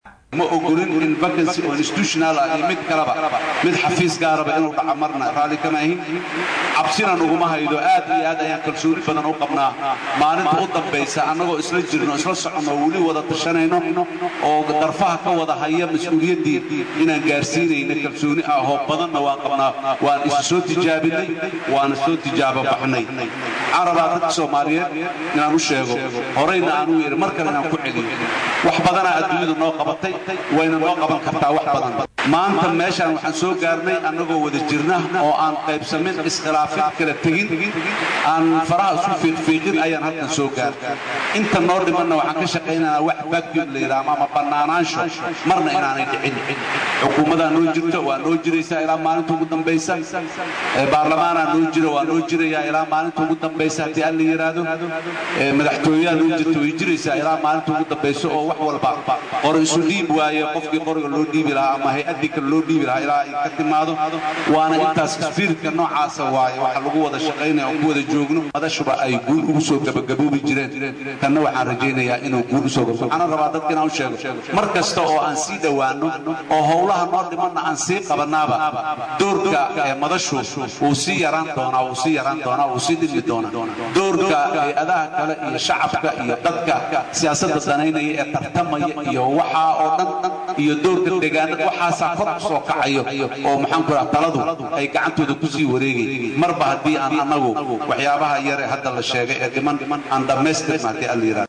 Madaxweynaha dowlada federaalka somaliya Xasan SHeekh Maxamuud oo la hadlayey warbaahinta muqdisho ayaa sheegey in doorashada macmalka ah ee la filaayo iney ka dhacdo dalka somaliya ay dhici doonto xiligii loogu tala galeywax dib u dhac ahna uusan ku imaan doonan.